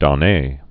(dŏ-nā, dô-)